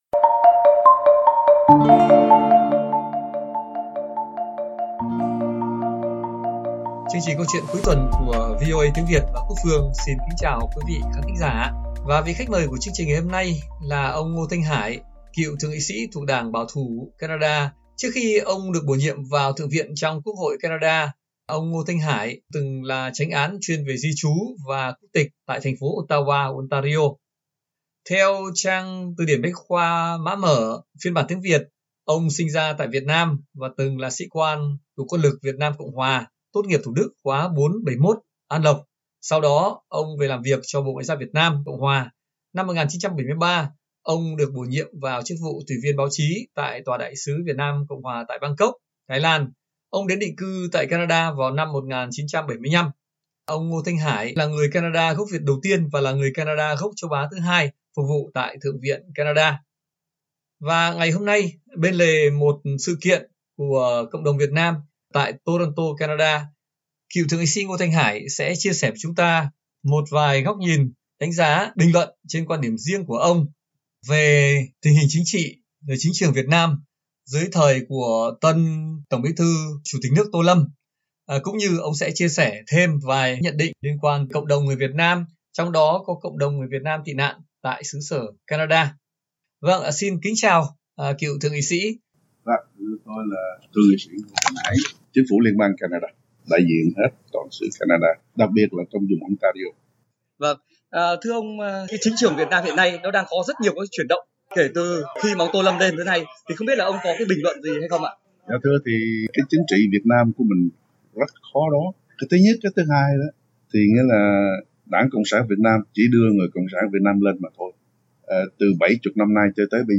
Bên lề một sự kiện trong cộng đồng Việt Nam tại Toronto, cựu Thượng nghị sĩ gốc Việt Ngô Thanh Hải chia sẻ bình luận, viễn kiến riêng về chính trường và tương lai của Việt Nam sau khi Tướng Tô Lâm nắm giữ hai ghế TBT và CTN; bên cạnh câu chuyện cộng đồng người Việt tị nạn tại Canada và sự hội nhập.